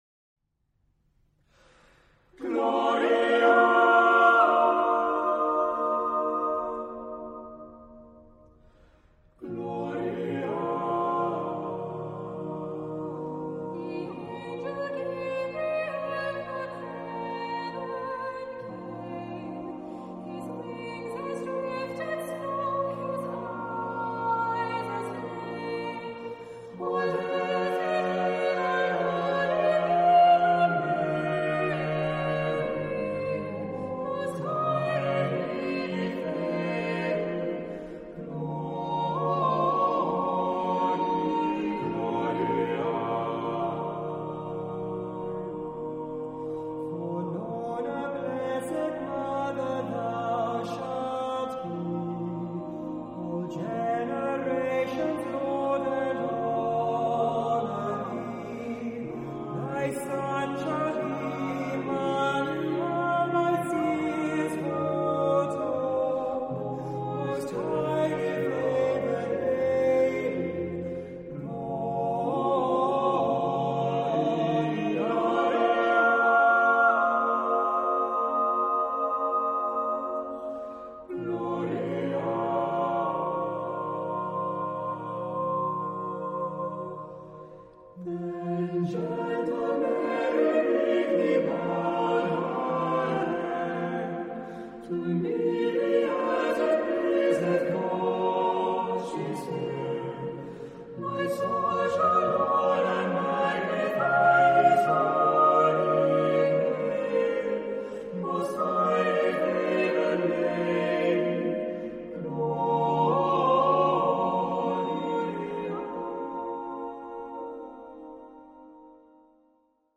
Charakter des Stückes: lebhaft
Chorgattung: SSAATTBB  (8 gemischter Chor Stimmen )
Tonart(en): b-moll